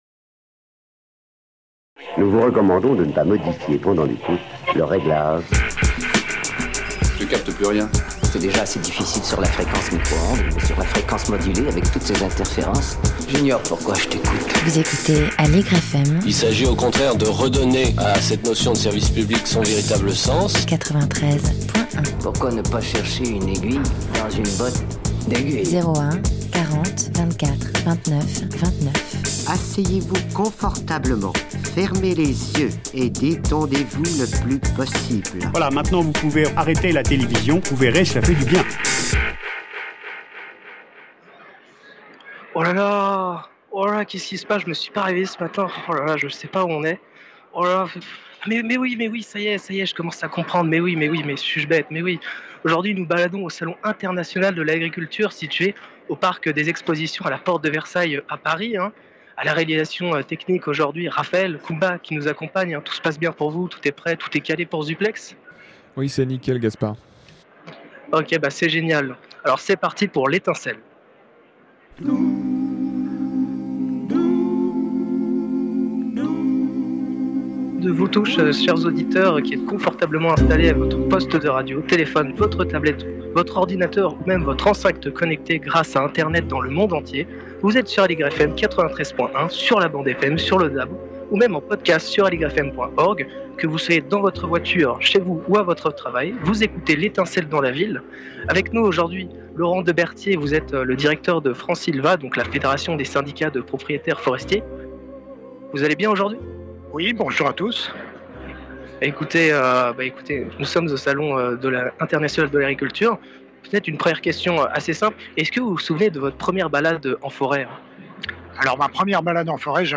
L'étincelle dans la ville est allée au Salon international de l'agriculture situé au Parc des expositions à Paris.